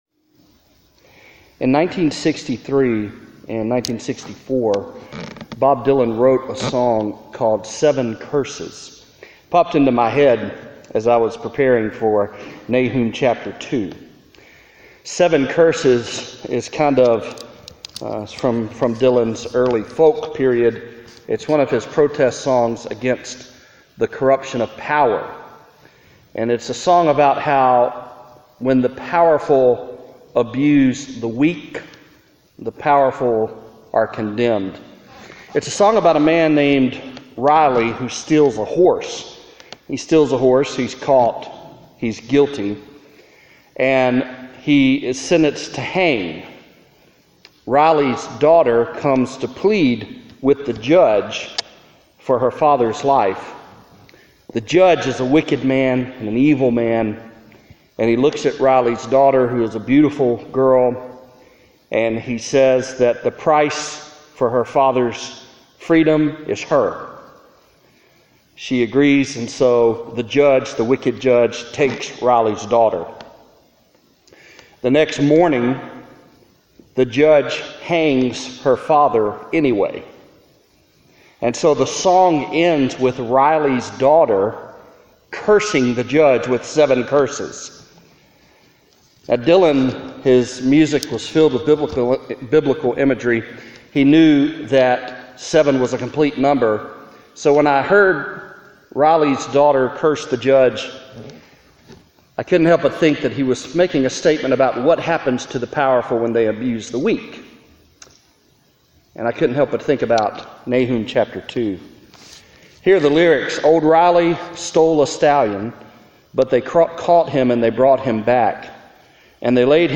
Nahum 1:1-6 (Preached at Central Baptist Church, North Little Rock, AR, on July 25, 2018) [manuscript]